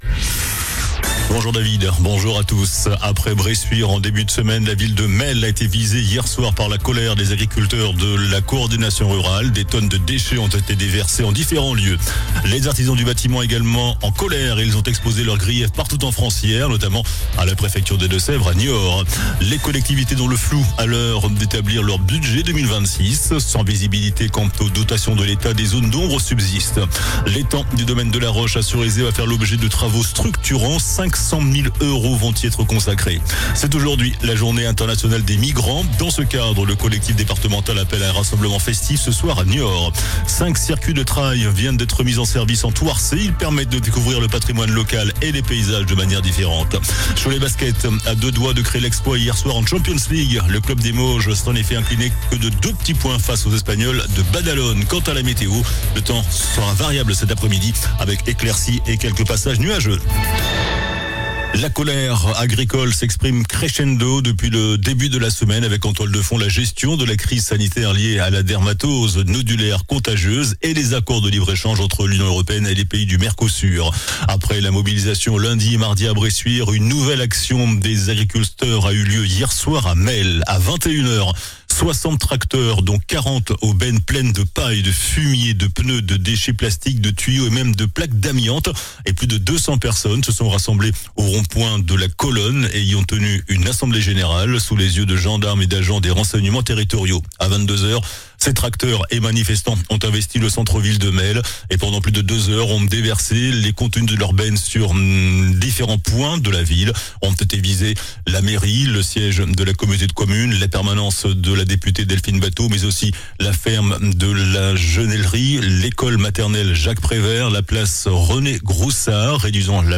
JOURNAL DU JEUDI 18 DECEMBRE ( MIDI )